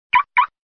Categoria Messaggio